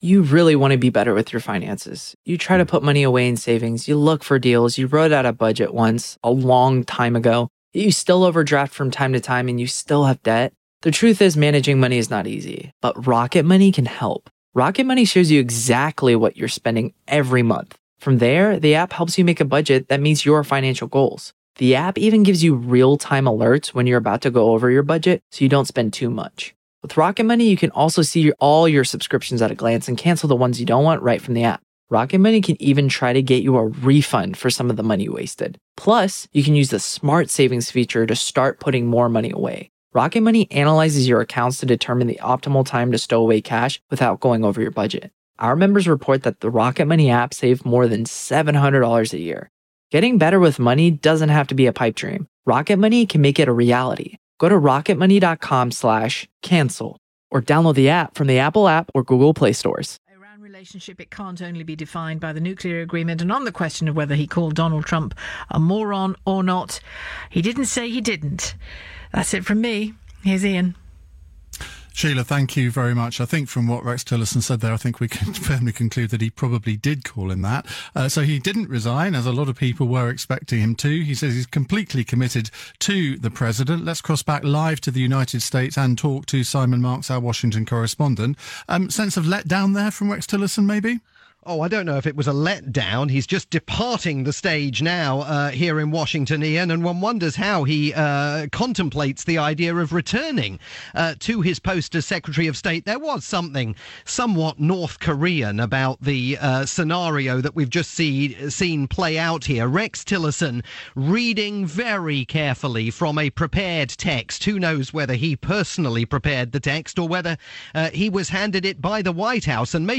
live coverage off-the-back of Rex Tillerson's public declaration of loyalty to President Trump, with Iain Dale on the UK's LBC.